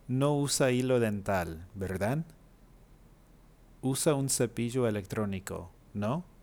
Listen to the audio clips and pay attention to the different intonations used in statements and questions.
Tag-questions.wav